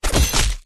Heavy_Sword4.wav